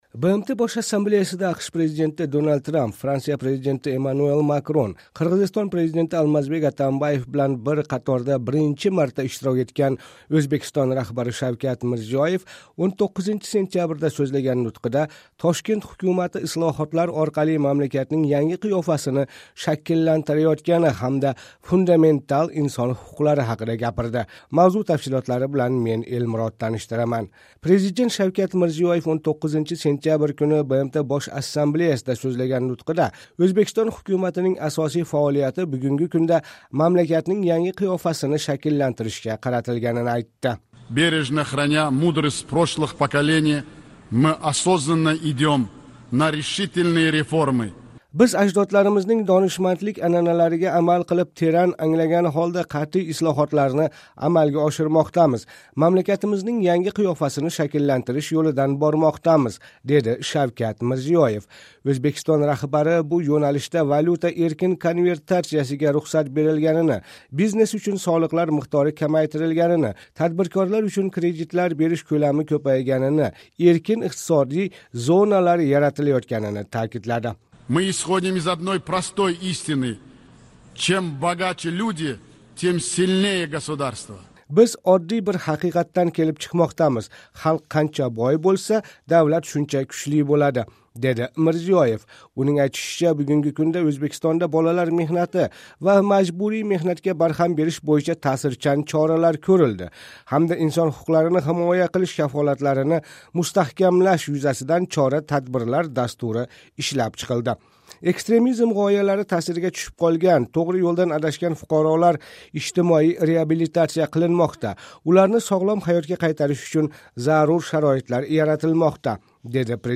БМТ Бош Ассамблеясида АҚШ президенти Дональд Трамп, Франция президенти Эммануэль Макрон, Қирғизистон президенти Алмазбек Атамбаев билан бир қаторда биринчи марта иштирок этган Ўзбекистон раҳбари Шавкат Мирзиёев 19 сентябрда рус тилида сўзлаган нутқида Тошкент ҳукумати ислоҳотлар орқали мамлакатнинг янги қиёфасини шакллантираётгани ҳамда фундаментал инсон ҳуқуқлари ҳақида гапирди.